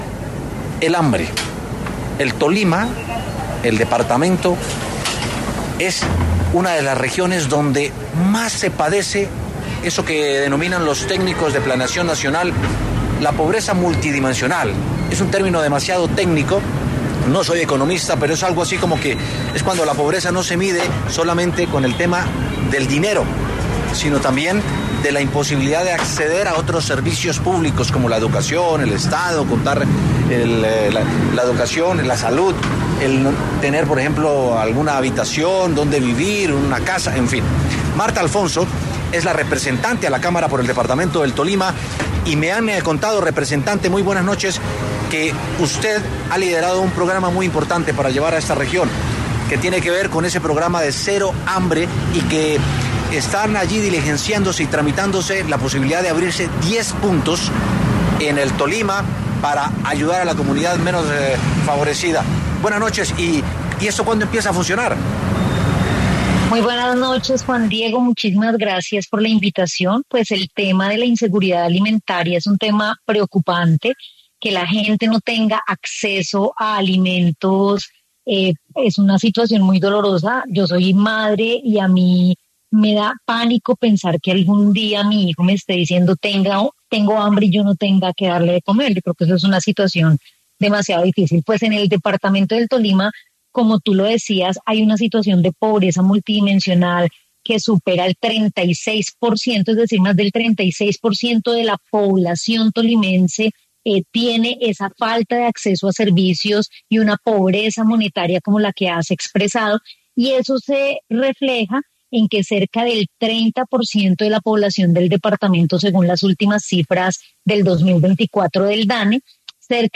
La congresista de Alianza Verde, Martha Alfonso, conversó con W Sin Carreta sobre la priorización del Tolima en los programas de alimentación de MinIgualdad.